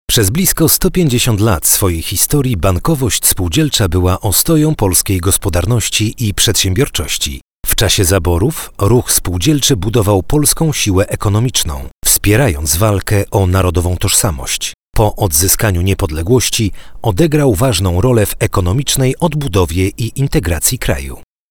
Professioneller polnischer Sprecher für TV/Rundfunk/Industrie.
Sprechprobe: Sonstiges (Muttersprache):